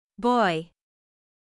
/ɔi/は、日本語の音では「オーェ」と表現できます。